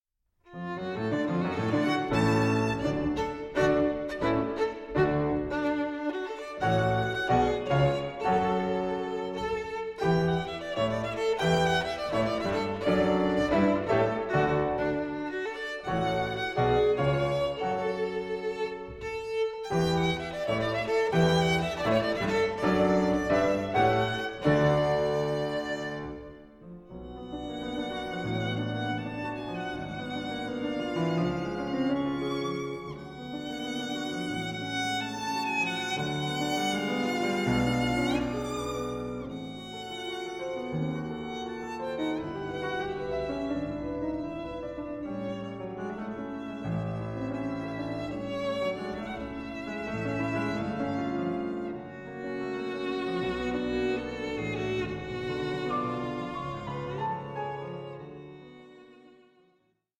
Nicht zu rasch 2:03